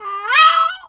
bird_peacock_hurt_03.wav